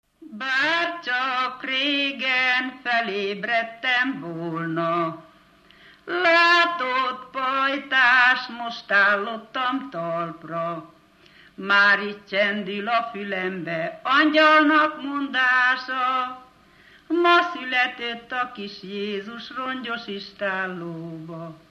Alföld - Csongrád vm. - Tápé
Műfaj: Betlehemes
Stílus: 6. Duda-kanász mulattató stílus